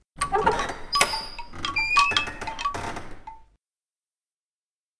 环境音 / 非循环音(SE)
0021_开花质木门.ogg